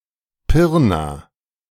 Pirna (German: [ˈpɪʁna]
De-Pirna.ogg.mp3